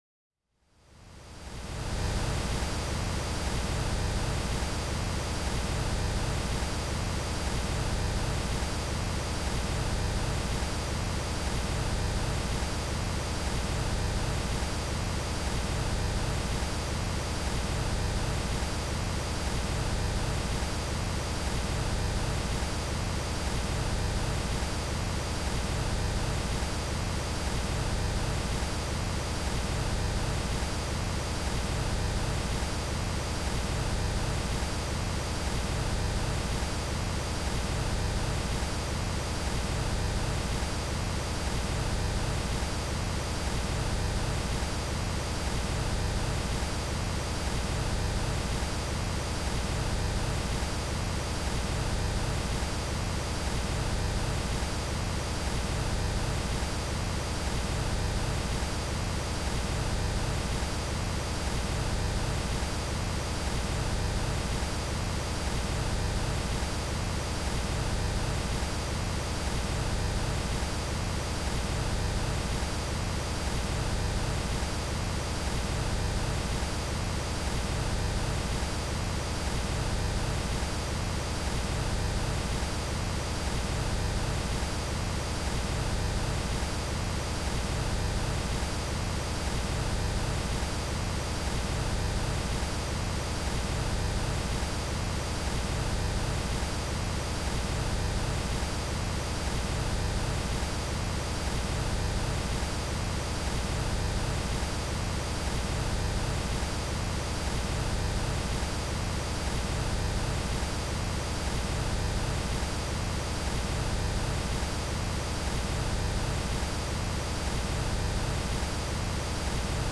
وایت نویز صدای اقیانوس
وایت نویز یه صدای یکنواخته که معمولاً شامل صداهایی مثل بارون، امواج دریا یا وزش باد میشه.
White-Noise-Oghyanoos.mp3